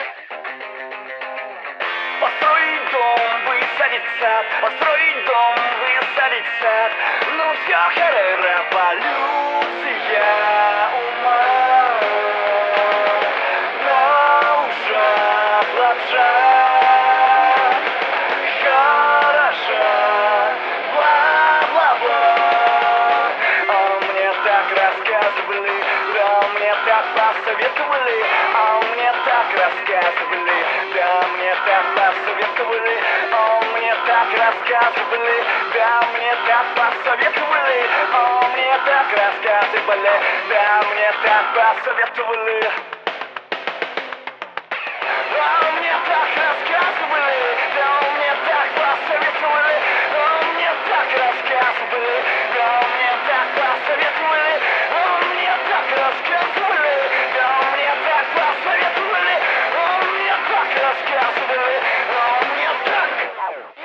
И энергетика. вроде как, появляется ))